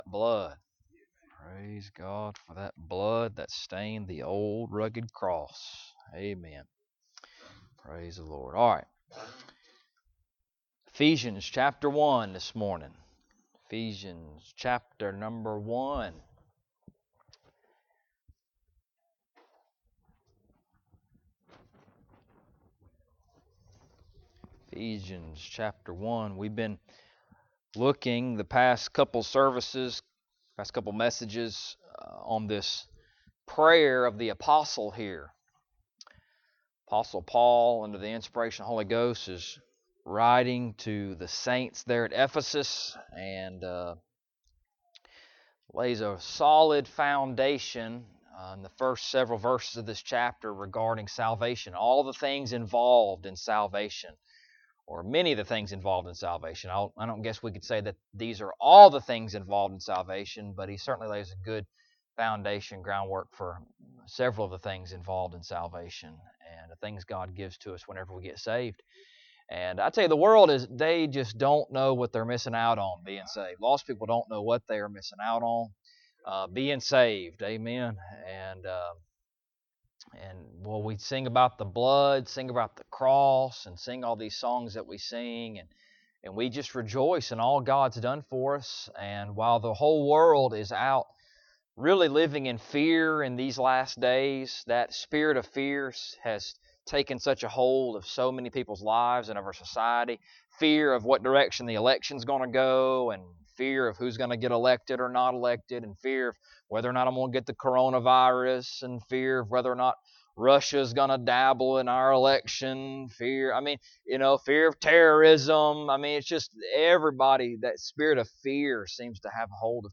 Ephesians Passage: Ephesians 1:15-19 Service Type: Sunday Morning Topics